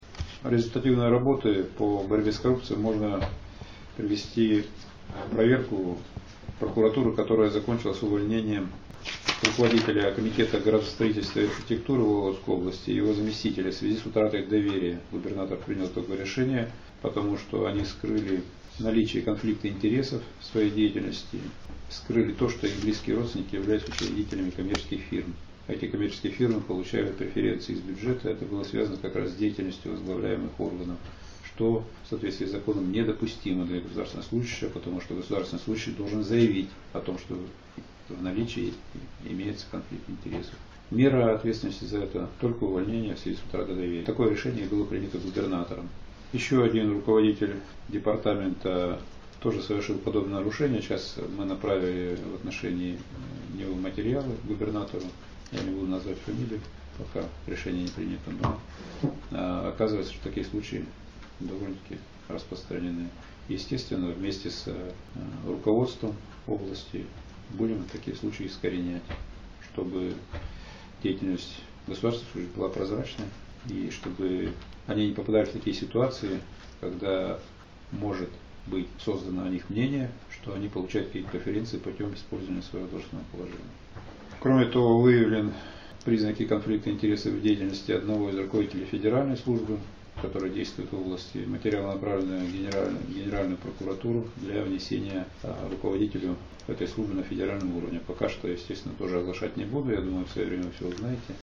Об этом заявил на встрече с журналистами прокурор области Сергей Хлопушин, но имя чиновника не назвал.
Сергей Хлопушин рассказывает о конфликте интересов